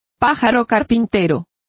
Complete with pronunciation of the translation of woodpeckers.